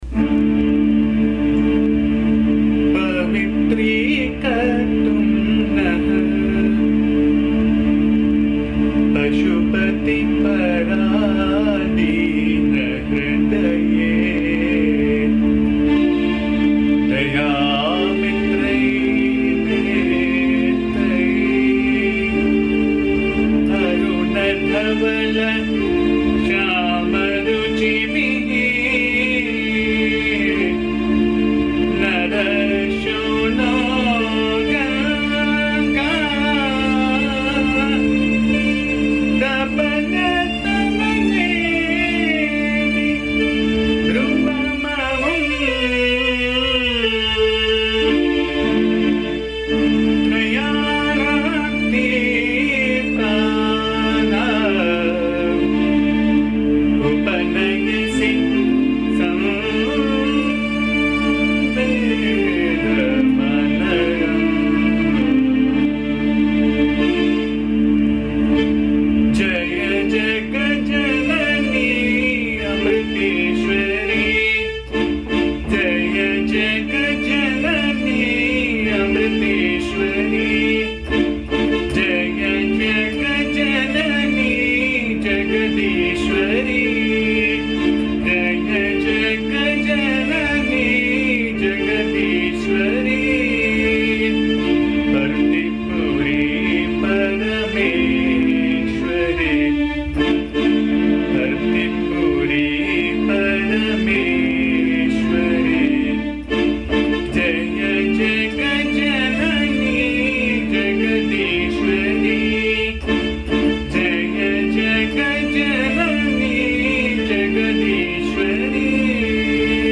This song is set in the Raga Abheri. It is a simple song which praises Devi as Nirguna Brahman and as creator-protector-destroyer of the world.
Please bear the noise, disturbance and awful singing as am not a singer.
AMMA's bhajan song